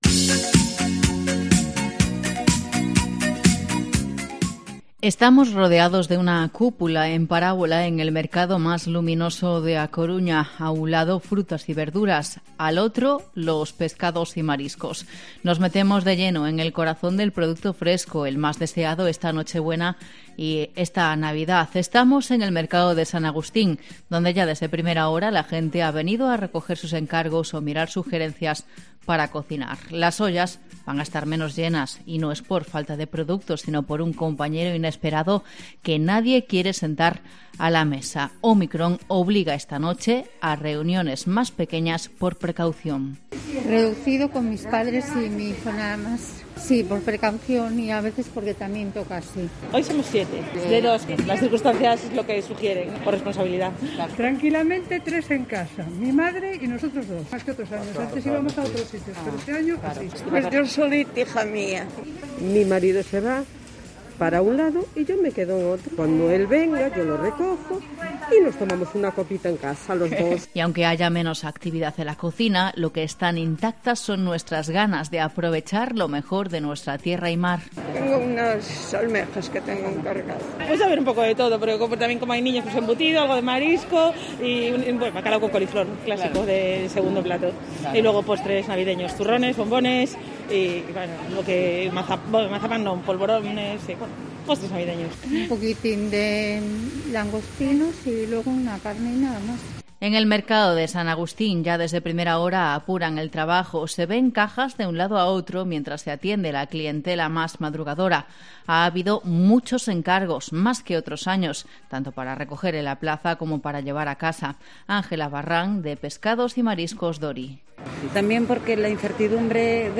Reportaje: el Mercado de San Agustín de A Coruña en Nochebuena 2021